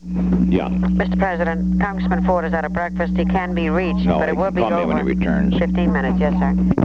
Location: White House Telephone
The White House operator talked with the President.